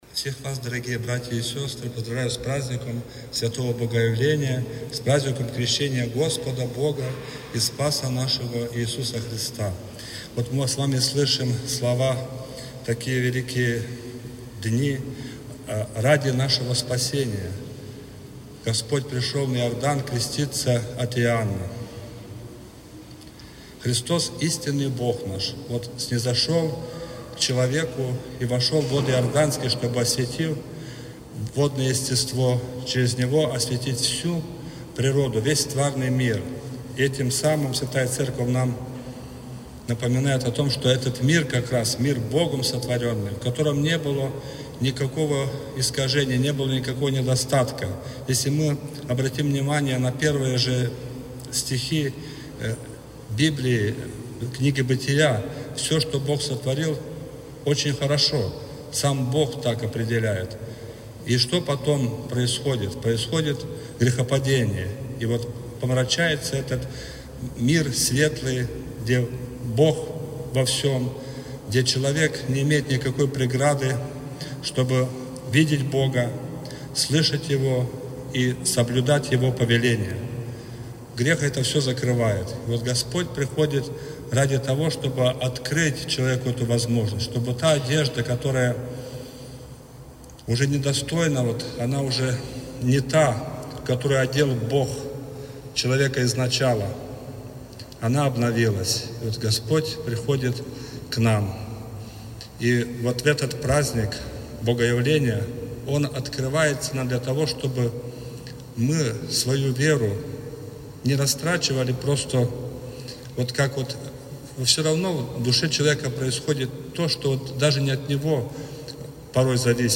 после вечернего богослужения